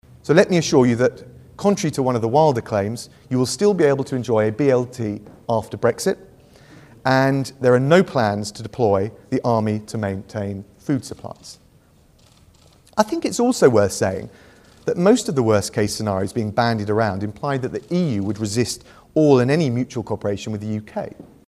Brexit Secretary Dominic Raab tried to address some of the potential issues: